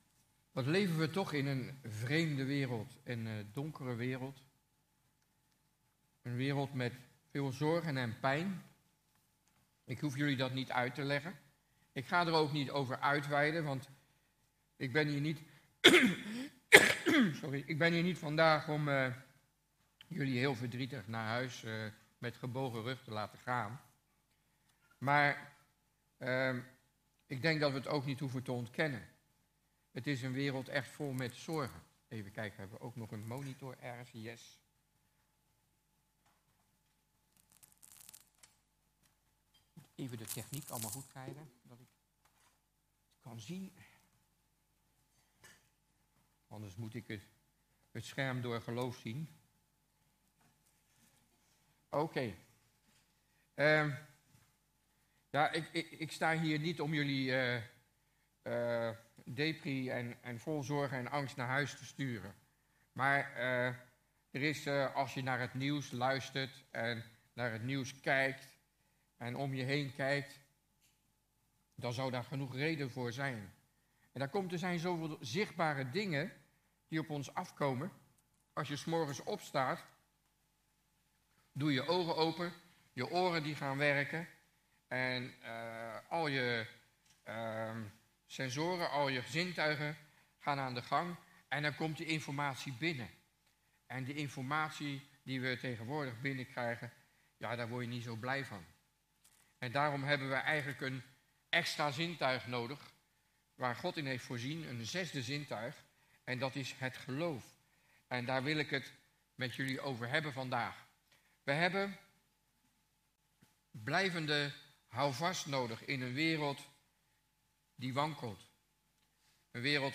Toespraak 27 maart: blijvend houvast: geloof - De Bron Eindhoven